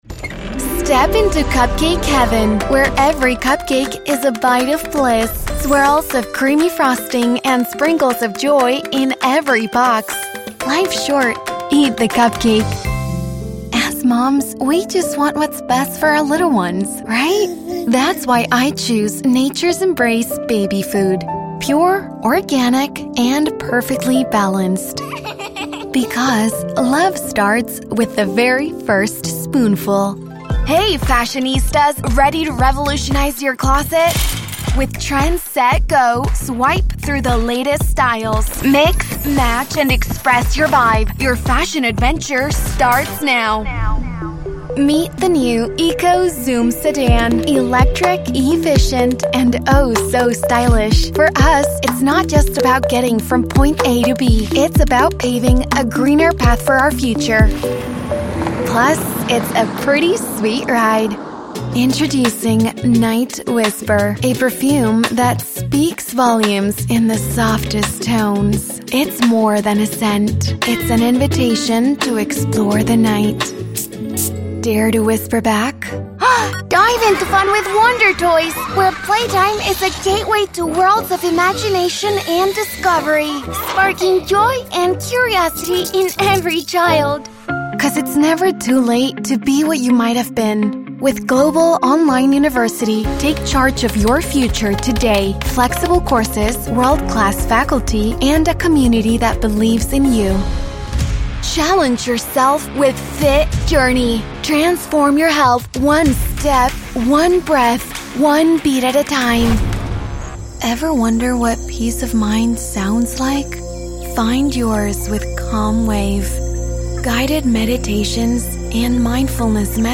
English (American)
Commercial Demo
🎙 PRO STUDIO
Warm, Caring & Smooth
Energetic, Upbeat, Fun & Sassy
Friendly, Informative & Conversational
Studiobricks Sound Booth
Neumann TLM 103